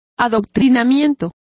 Complete with pronunciation of the translation of indoctrination.